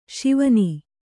♪ Śivani